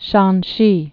(shänshē) also Shen·si (shĕnsē)